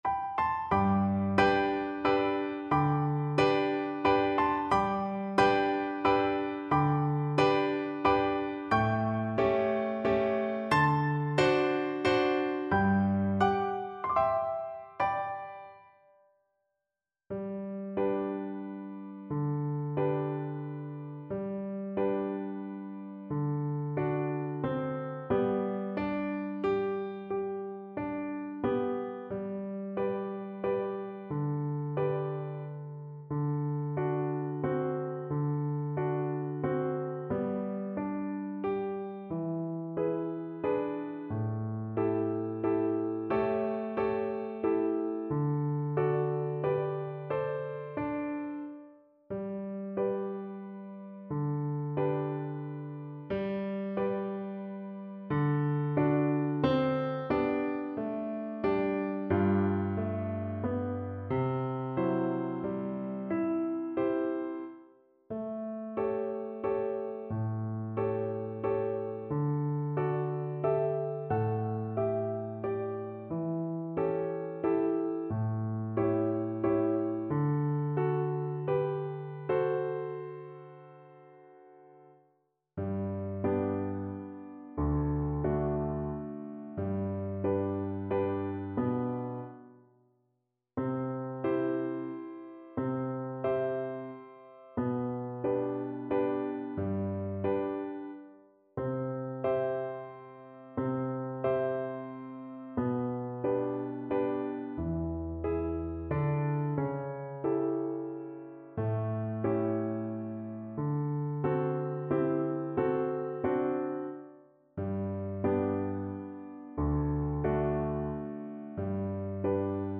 Valse moderato espressivo = 120
3/4 (View more 3/4 Music)